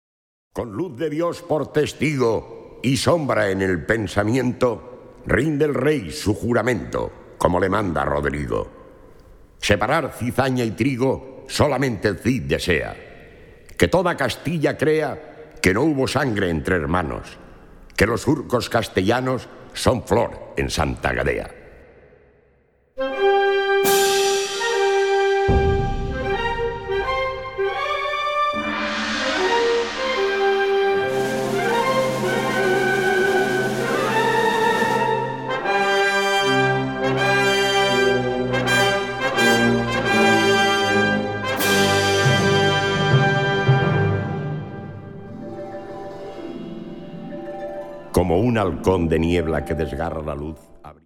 Categorie Harmonie/Fanfare/Brass-orkest
Bezetting Ha (harmonieorkest); SprS (verteller)